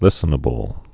(lĭsə-nə-bəl)